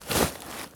foley_sports_bag_movements_02.wav